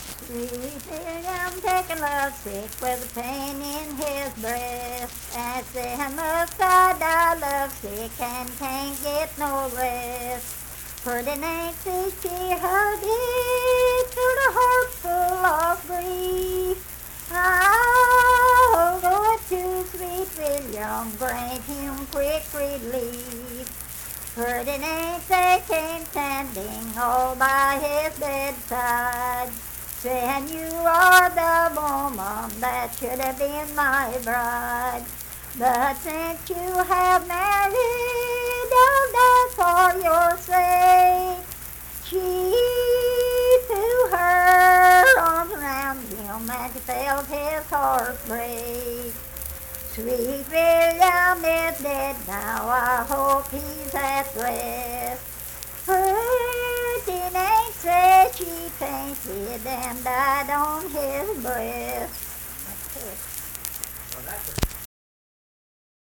Unaccompanied vocal music performance
Verse-refrain 3d(4).
Voice (sung)